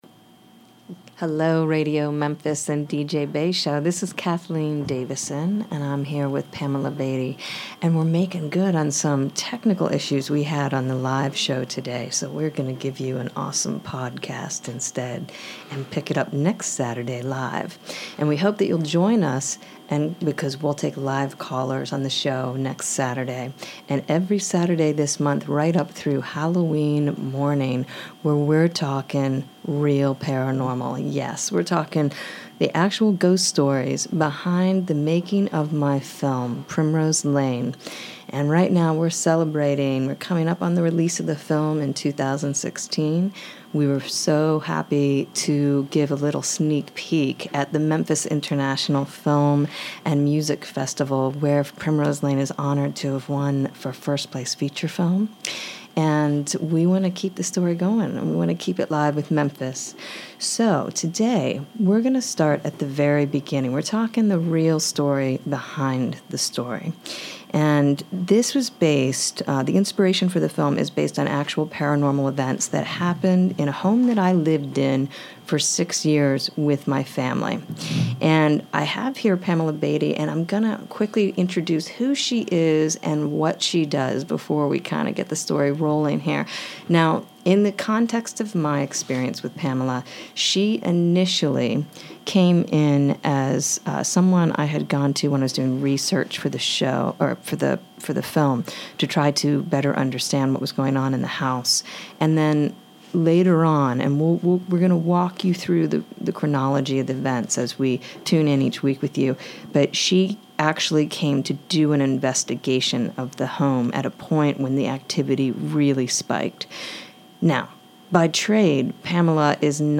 ongoing discussion